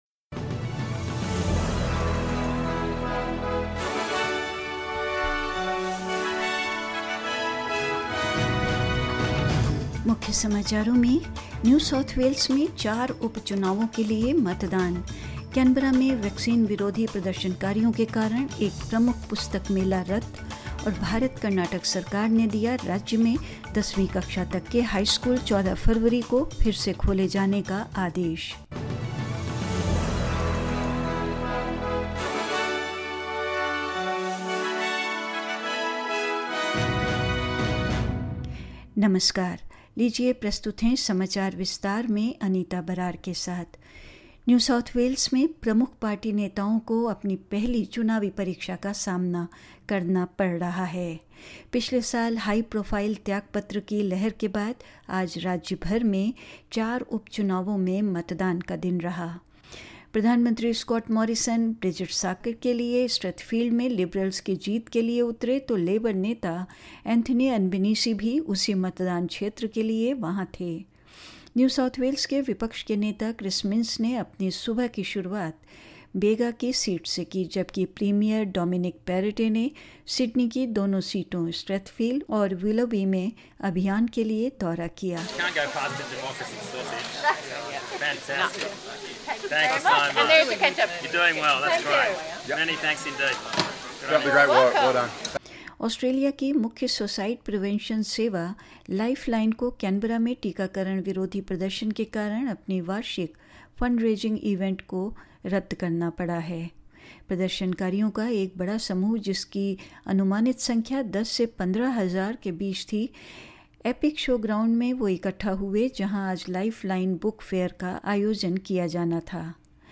In this latest SBS Hindi bulletin: The polls for four by-elections today in New South Wales; A major book fair is cancelled as anti-vaccine protestors converge in Canberra; In India, students of Class 9 and Class 10 in Karnataka will return to classes from Monday, February 14 and more news.